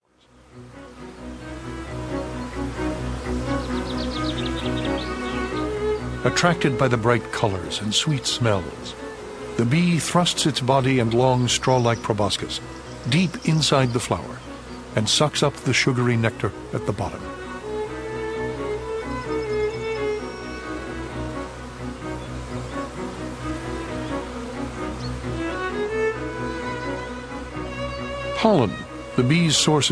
one hour talk on Honey Bees